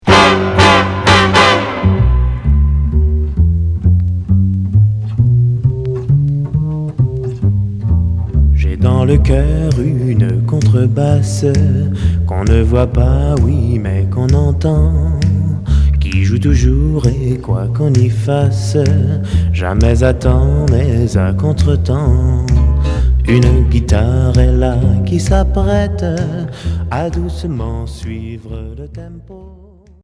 Jazz Pop Premier 45t retour à l'accueil